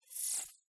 На этой странице собраны звуки голограмм — загадочные и футуристические аудиоэффекты, напоминающие технологии из научной фантастики.
Звук, в котором голограмма свернулась